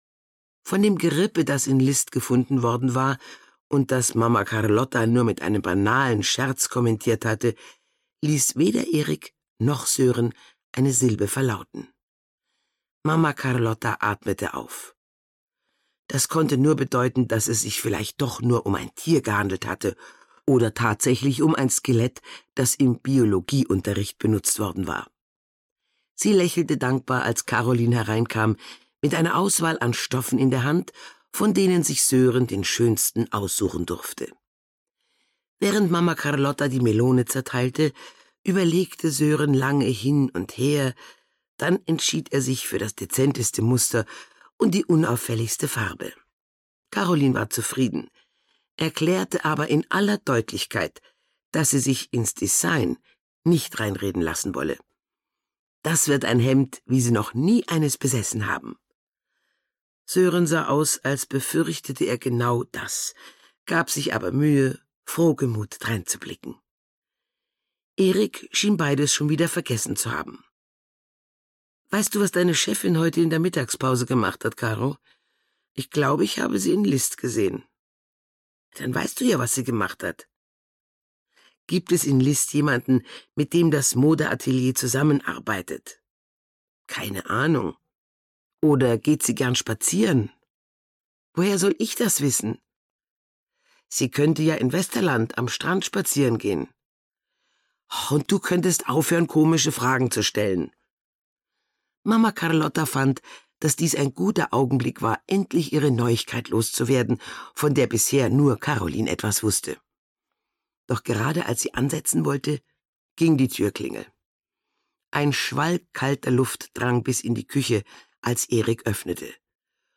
Flammen im Sand (Mamma Carlotta 4) - Gisa Pauly - Hörbuch